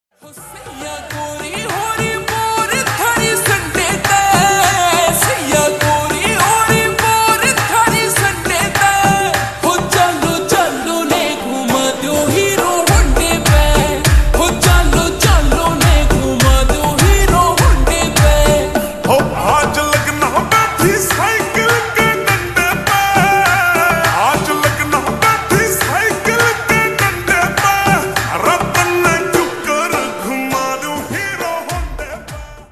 Haryanvi Song
(Slowed + Reverb)